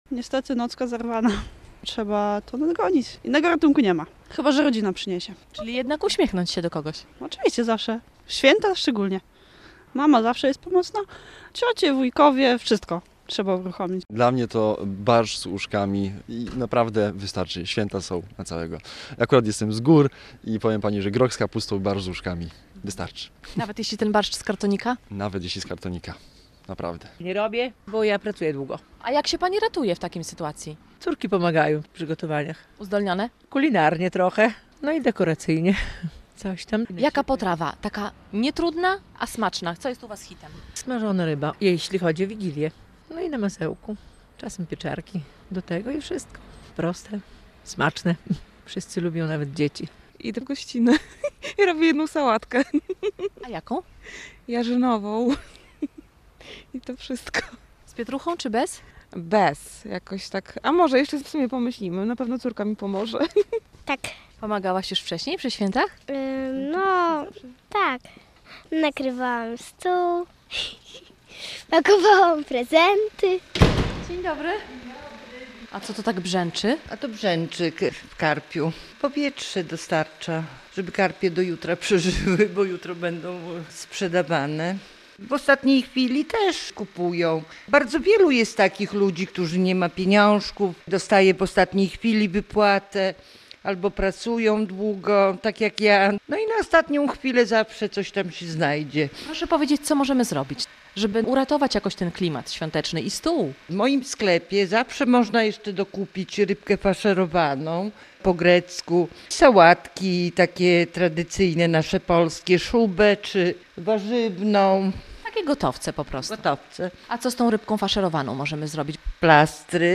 relacja
Nasza reporterka pytała o to białostoczan.